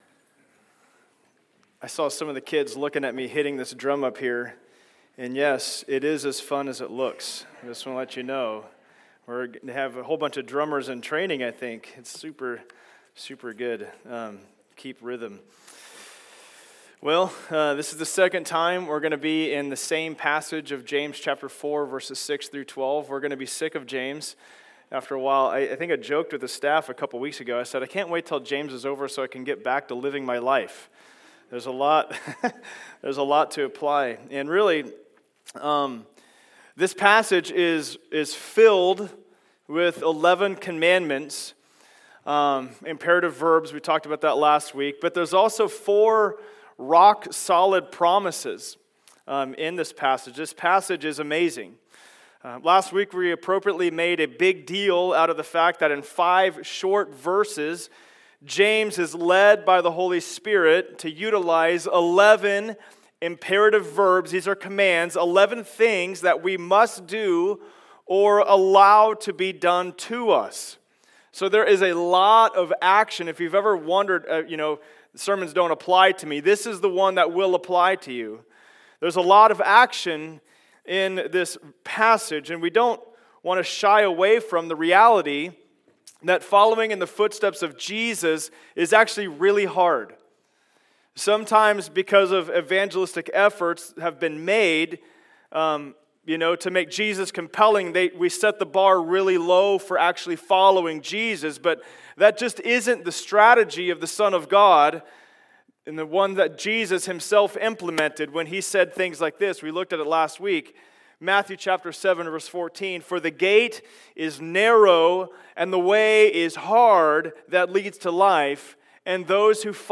From Knowing to Doing Service Type: Sunday Service Download Files Notes « The Pathway To Life Is…